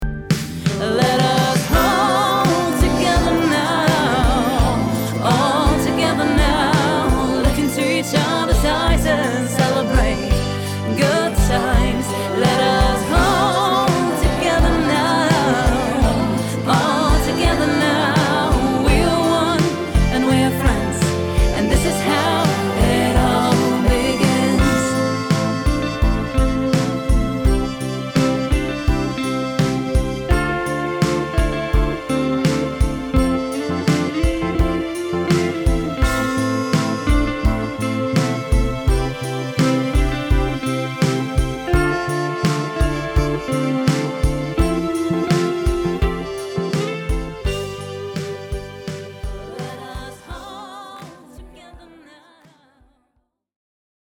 Authentisch und voller Gefühl erinnert ihre Single daran,